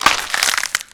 break4.ogg